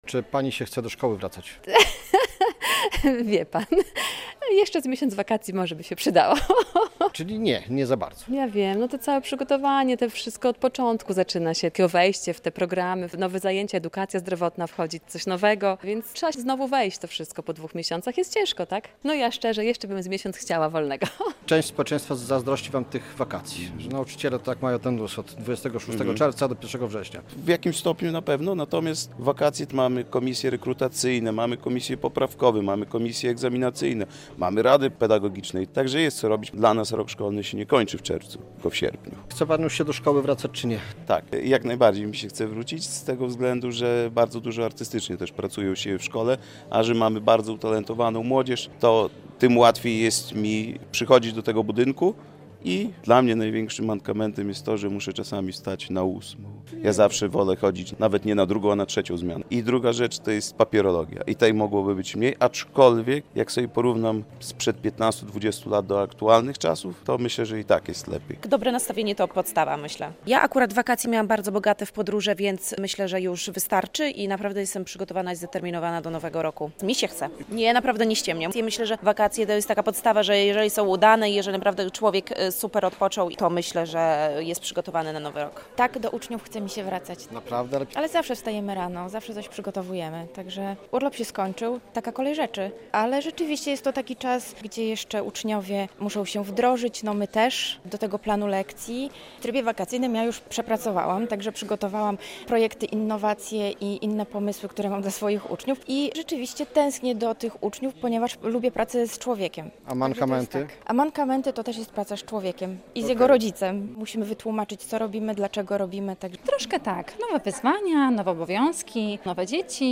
Nauczyciele obalają mity o "dwumiesięcznych wakacjach" - relacja